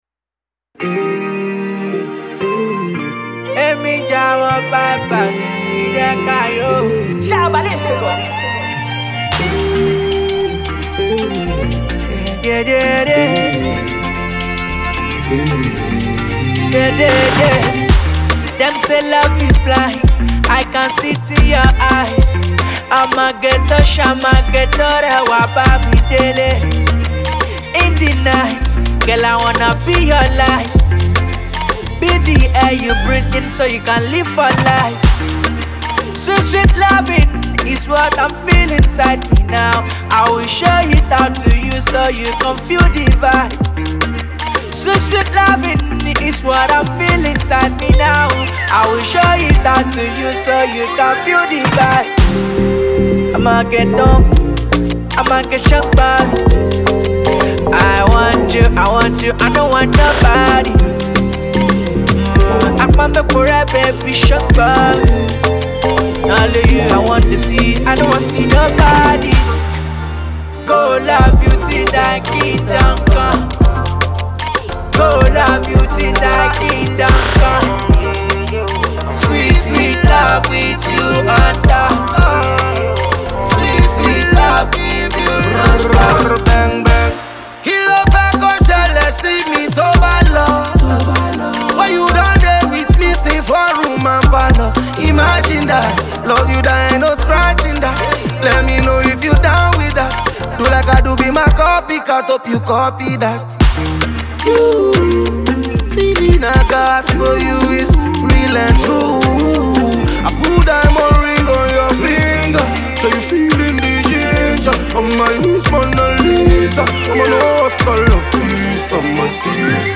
Nigerian sensational singer
The groovy joint anthem
seamlessly combines many musical styles